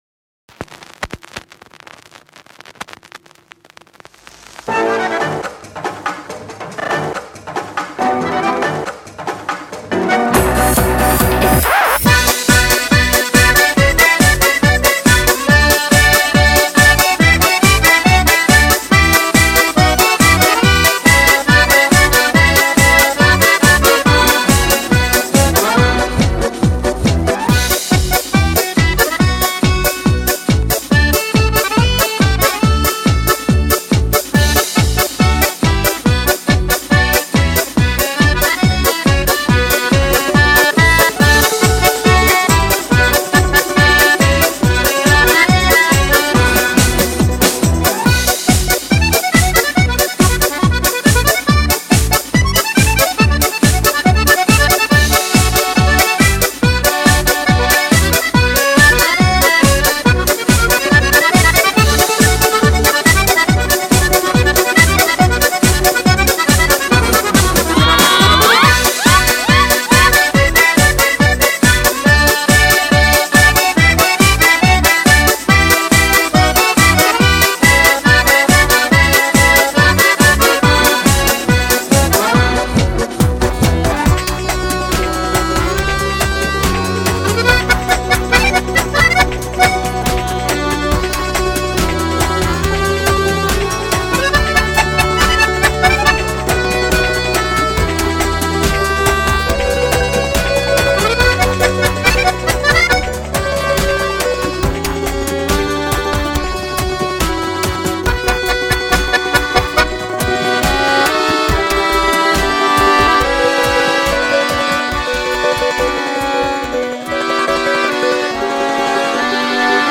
Вышла на перерыв Осталась минусовка.